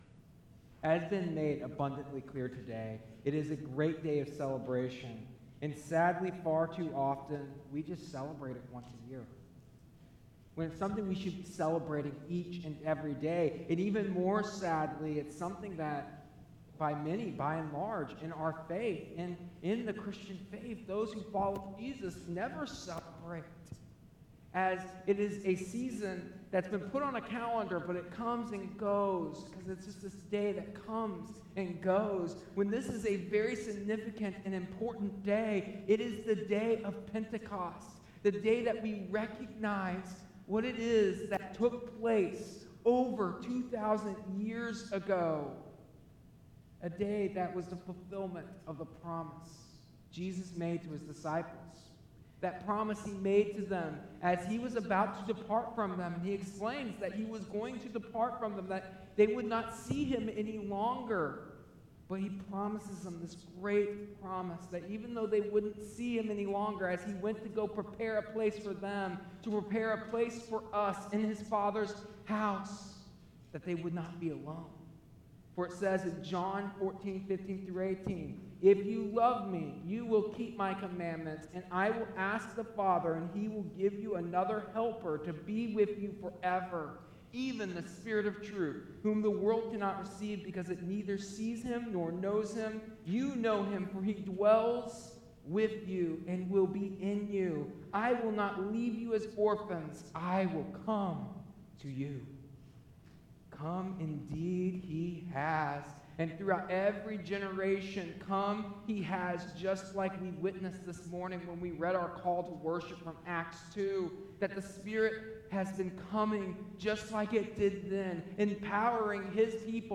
Today’s message addresses the vital question of “What’s Next?” during a sermon celebrating graduates, emphasizing that this question is relevant […]